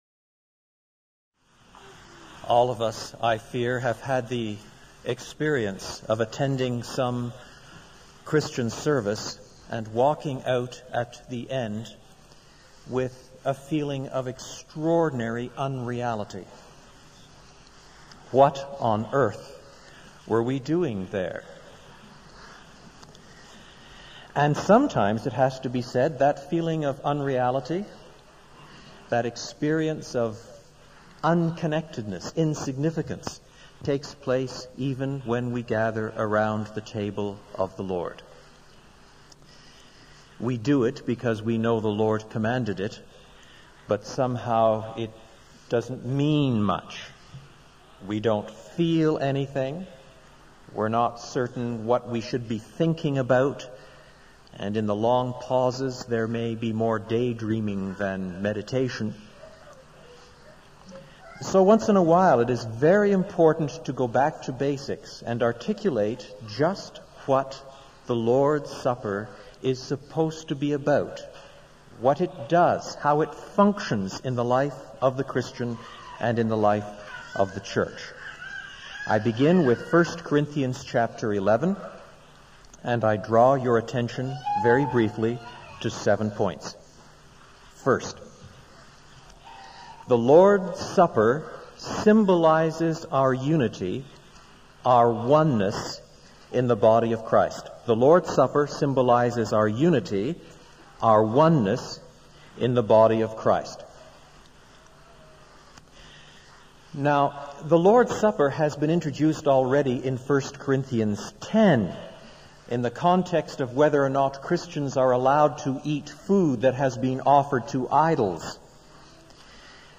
A sermon preached in 2008 on 1 Corinthians 11:17–31.